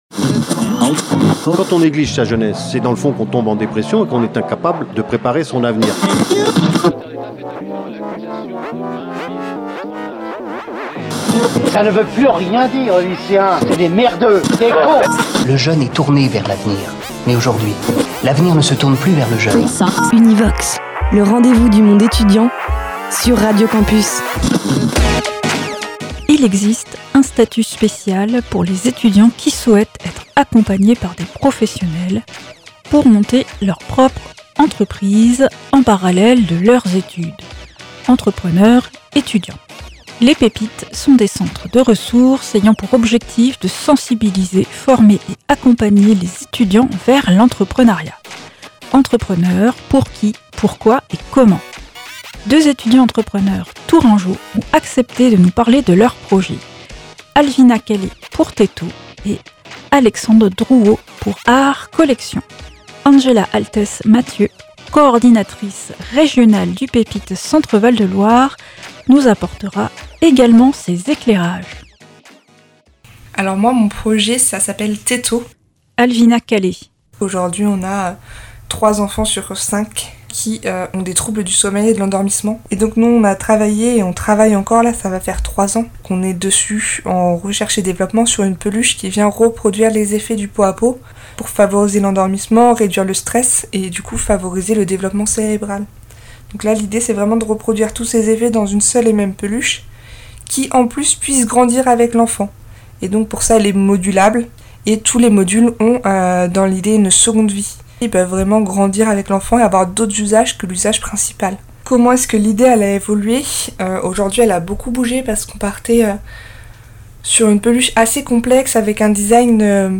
À Radio Campus, on a posé nos micros à Tours